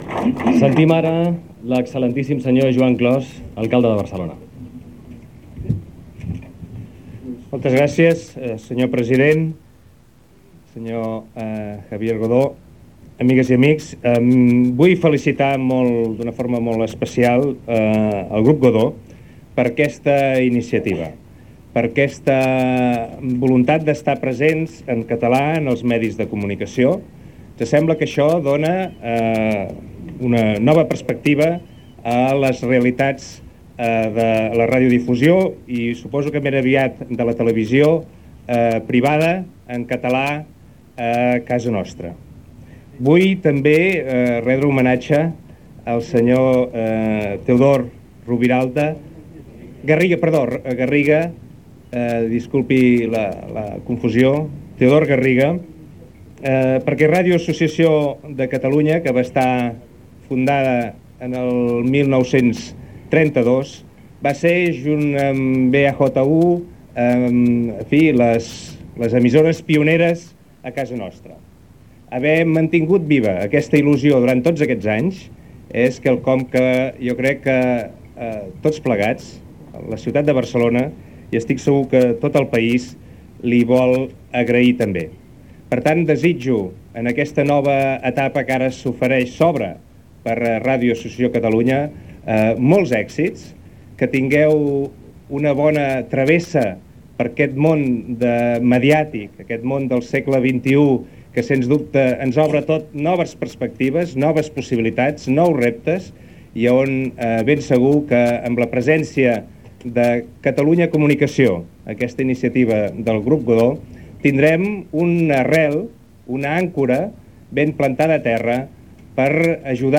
9acef8a2291c8efd41d7fec0614e2c8aa137d924.mp3 Títol RAC 1 Emissora RAC 1 Barcelona Cadena RAC Titularitat Privada nacional Descripció Discurs de l'alcalde de Barcelona, Joan Clos, el dia de la inauguració de l'emissora.